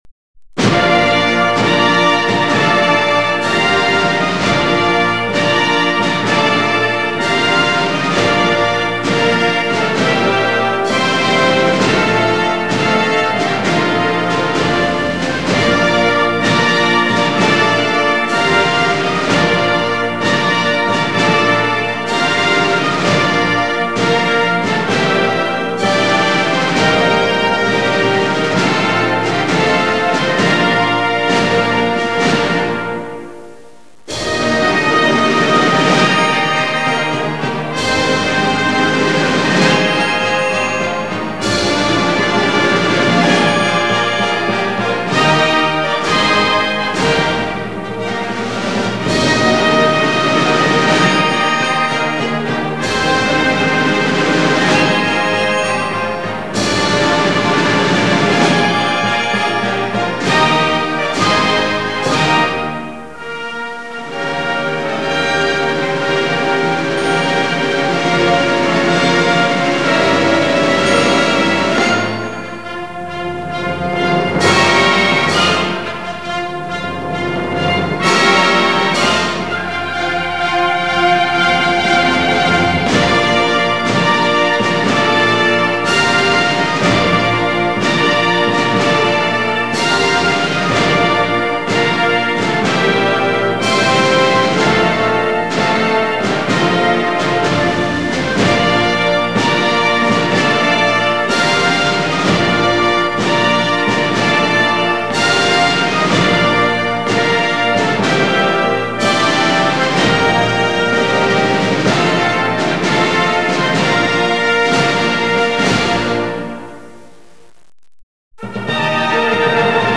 Marche-du-Garde-Consulaire.mp3